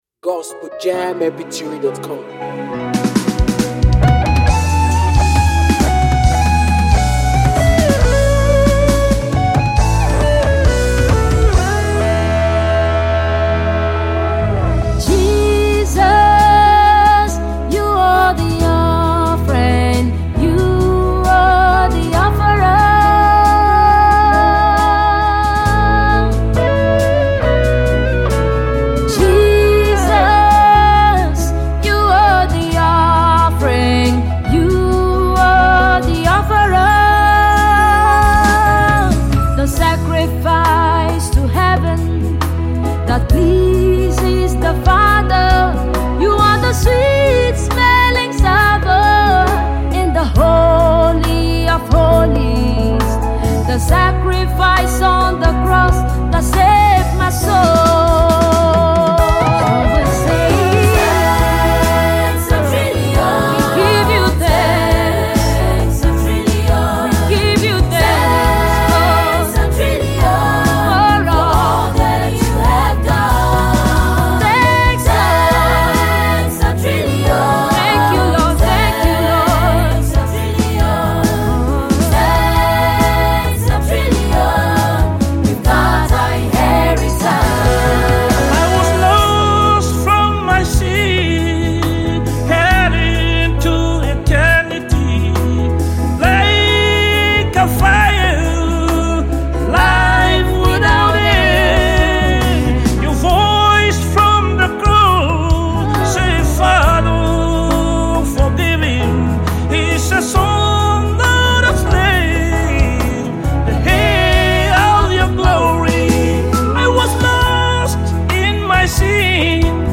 A Powerful Gospel Collaboration*
gospel single